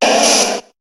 Cri d'Ortide dans Pokémon HOME.